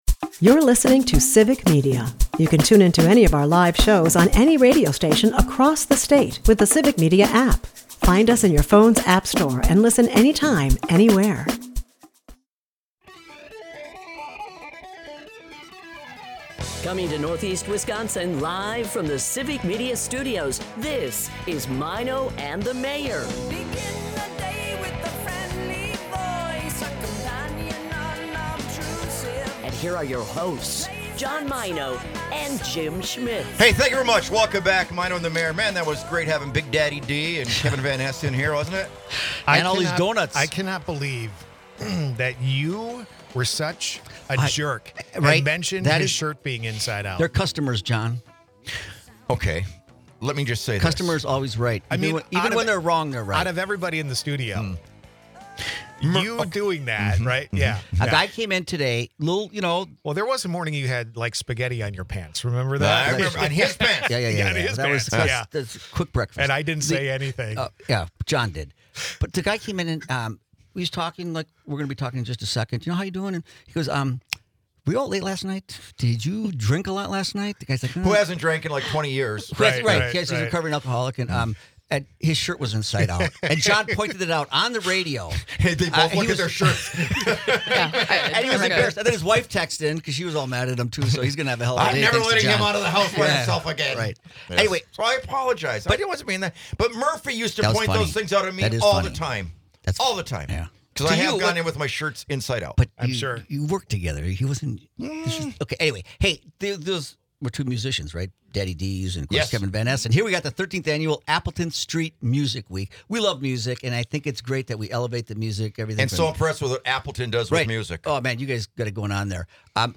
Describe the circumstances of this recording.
and a spirited live music performance by the mother-son duo